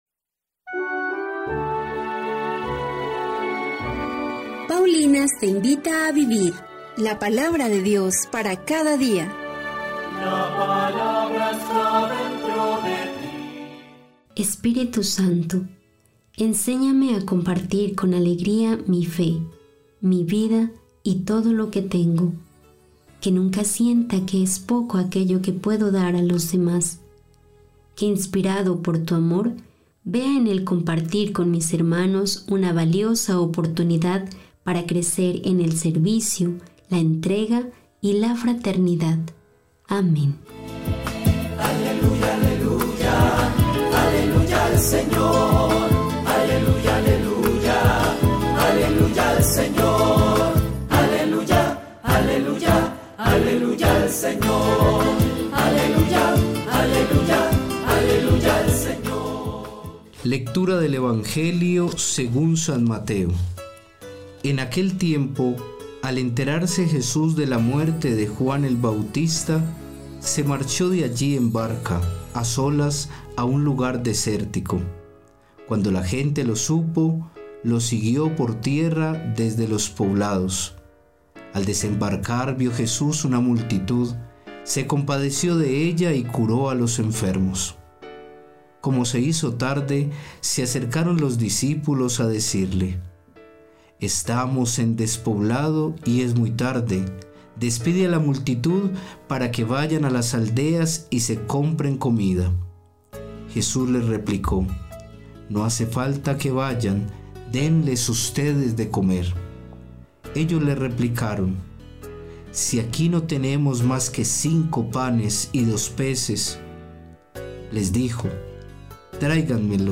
Lectura del libro del Levítico 25, 1. 8-17